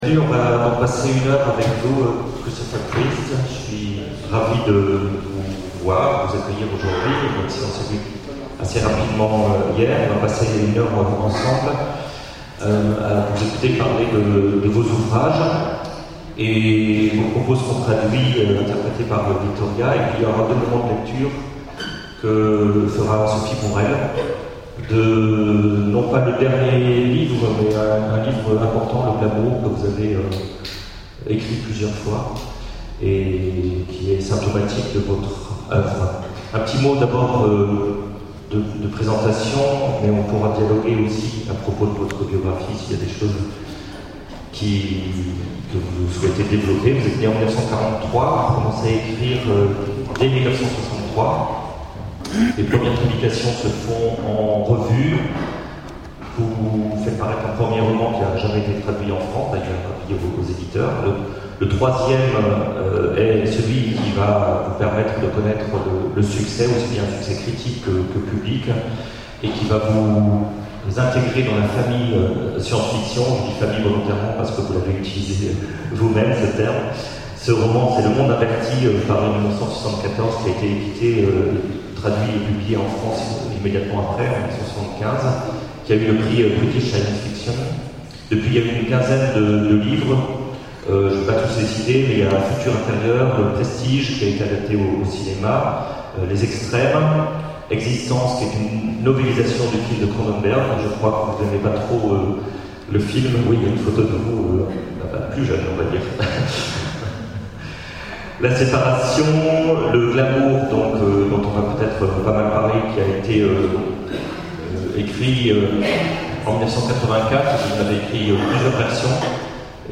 Comédie du livre 2012 : Rencontre avec Christopher Priest
Rencontre avec un auteur Conférence